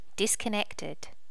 disconnected.wav